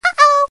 Звуки уведомлений о сообщениях
Звук сповіщення аськи (в ICQ прийшло повідомлення)